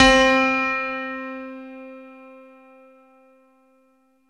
Index of /90_sSampleCDs/Syntec - Wall of Sounds VOL-2/JV-1080/SMALL-PIANO
E-GRAND LM 21.wav